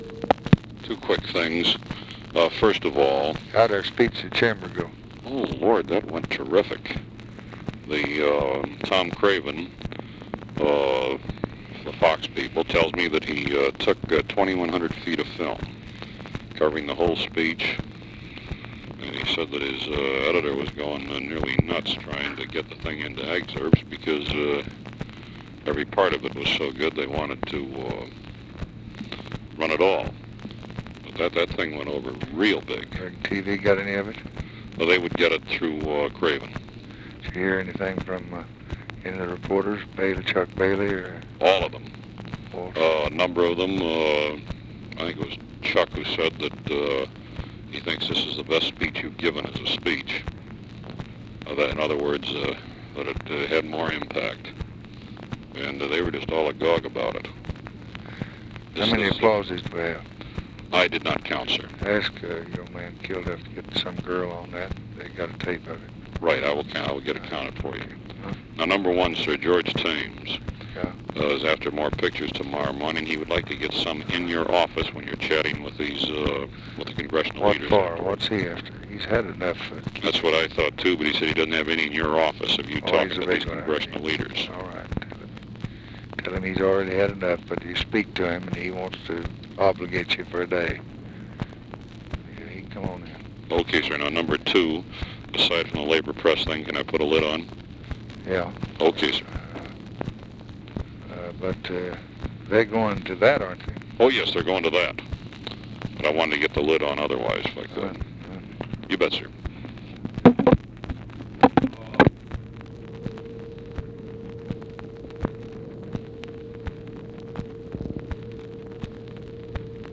Conversation with GEORGE REEDY, April 27, 1964
Secret White House Tapes